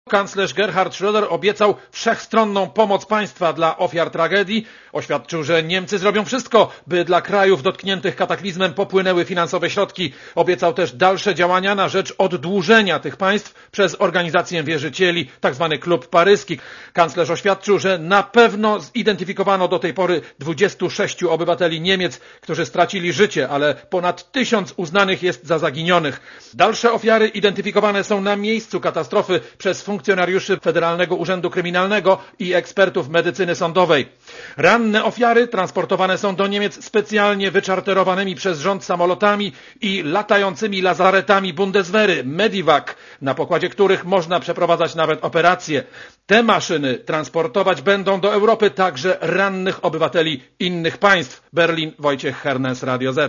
Korespondencja z Berlina